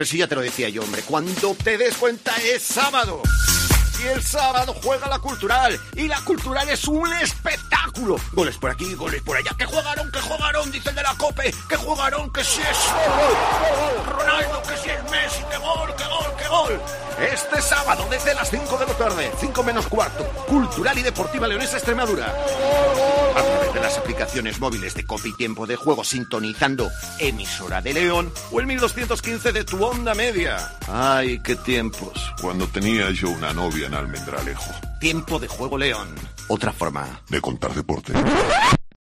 Escucha la cuña promocional del partido Cultural - Extremadura el día 16-10-21 a las 17:00 h en el 1.215 OM